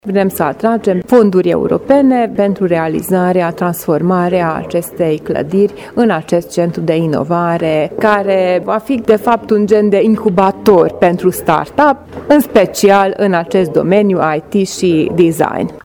Viceprimarul municipiului, Sztakics Eva: